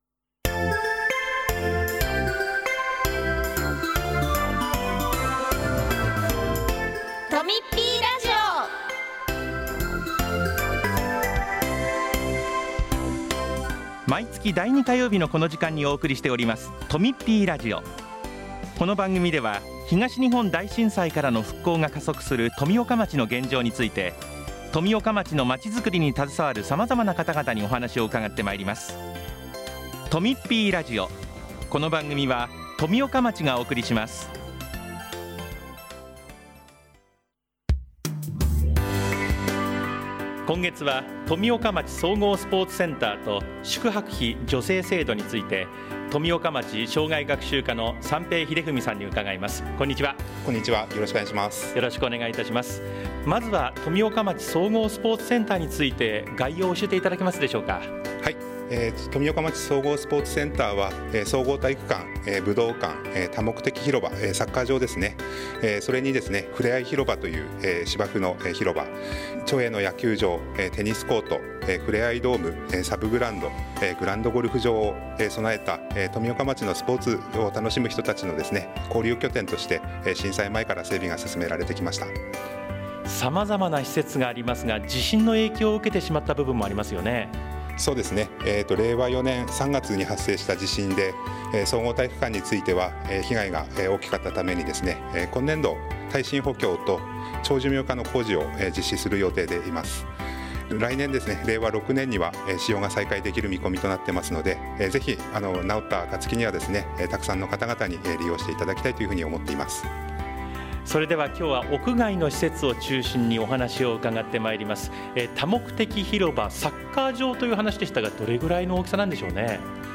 6月13日（火曜日）に放送した「とみっぴーラジオ」を、お聴きいただけます。